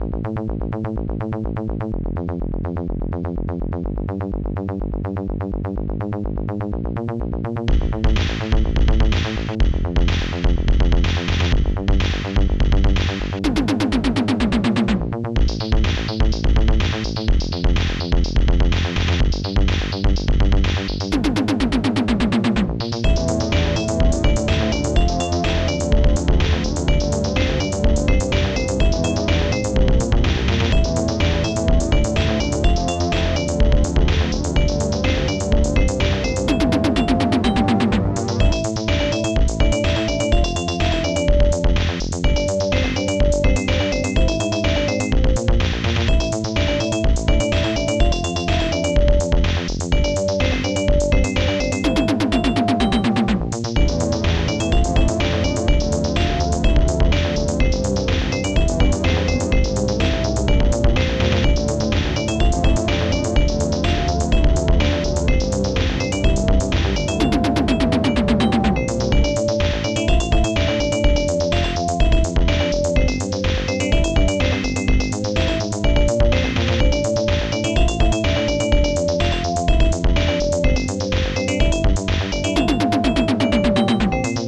Protracker Module
Title crazyhit2 Type Protracker and family
Instruments st-01:horns st-01:digdug st-01:popsnare2 st-01:bassdrum3 st-01:electom st-01:hihat2 st-01:analogstring st-01:celeste